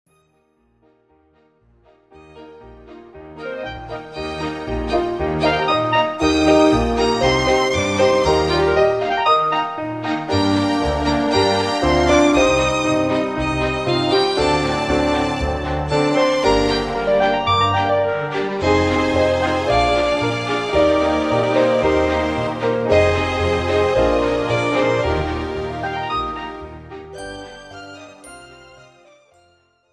Classical and Opera
OPERA